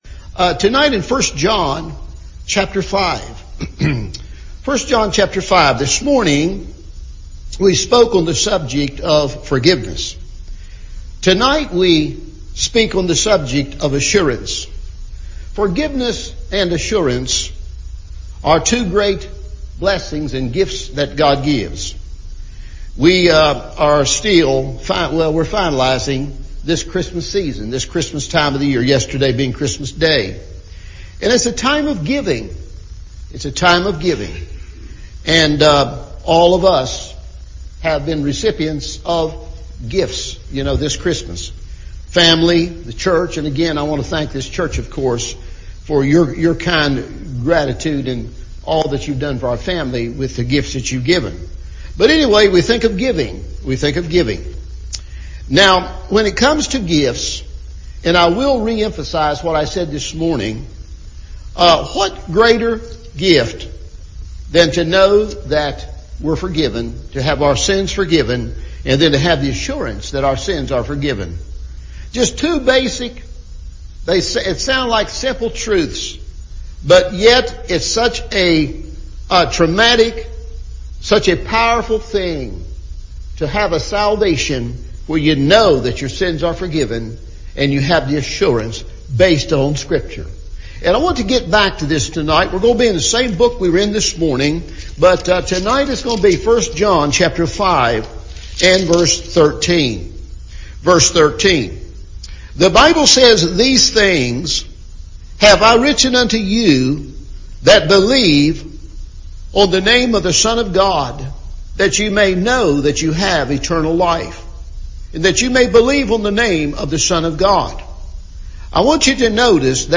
Assurance – Evening Service